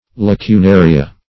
Lacunaria synonyms, pronunciation, spelling and more from Free Dictionary.